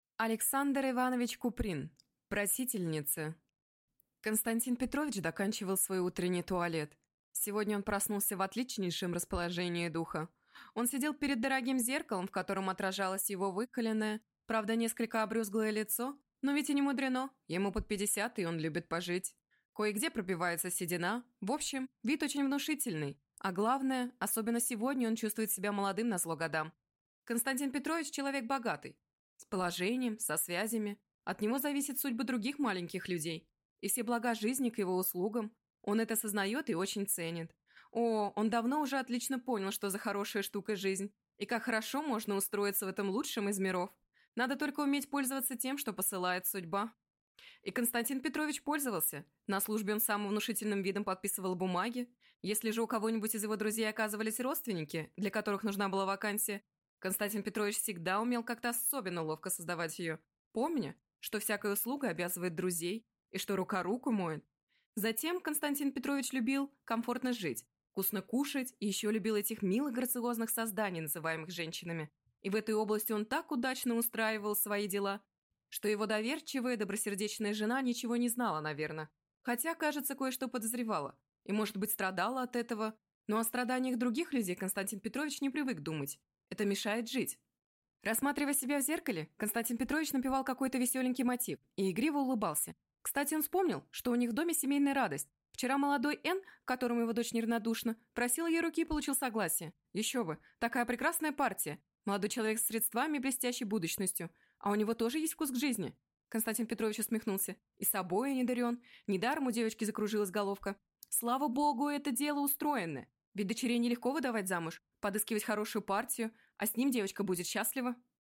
Аудиокнига Просительница | Библиотека аудиокниг